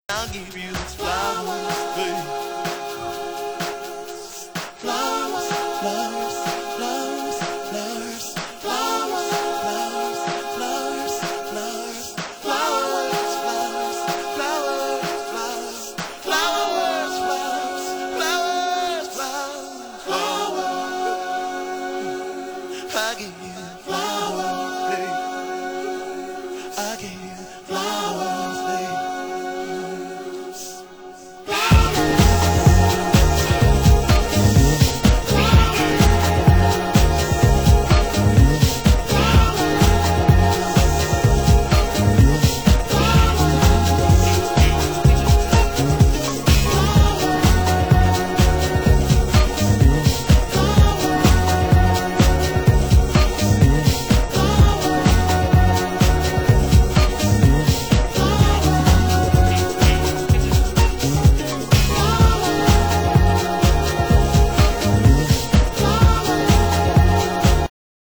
★DEEP HOUSE 歌